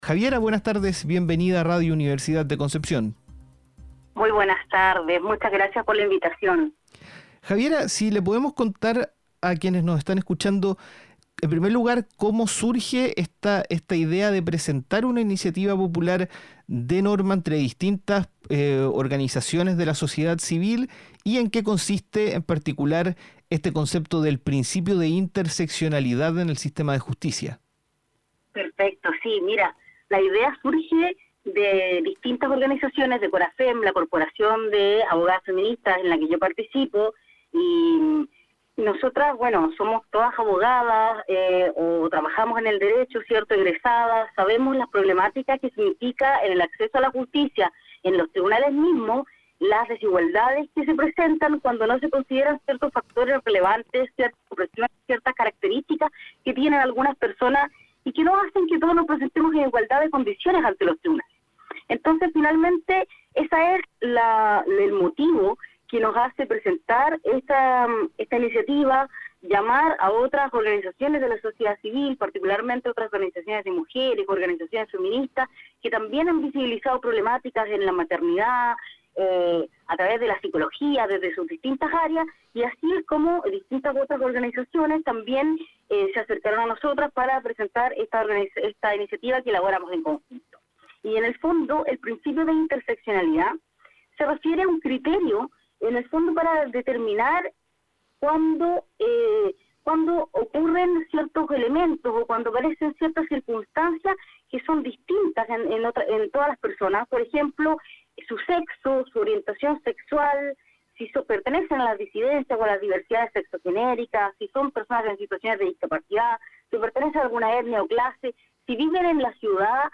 En conversación con Nuestra Pauta